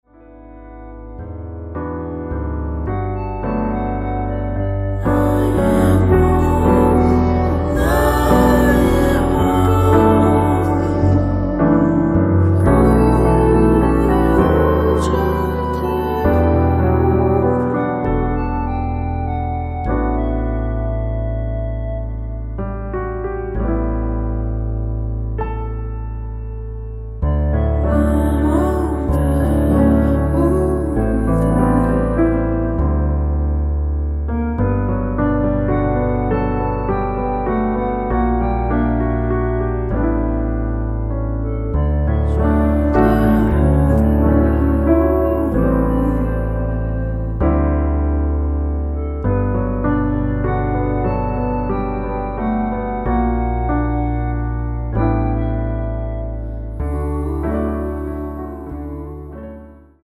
원키에서(-1)내린 멜로디와 코러스 포함된 MR입니다.(미리듣기 확인)
Db
앞부분30초, 뒷부분30초씩 편집해서 올려 드리고 있습니다.
중간에 음이 끈어지고 다시 나오는 이유는